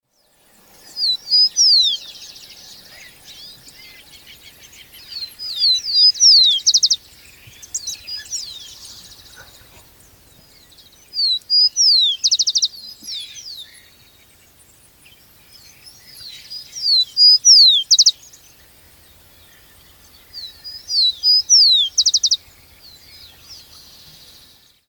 Tico-tico (Zonotrichia capensis)
Nome em Inglês: Rufous-collared Sparrow
Fase da vida: Adulto
Detalhada localização: Camino ribereño del Rio Quequén Grande
Condição: Selvagem
Certeza: Observado, Gravado Vocal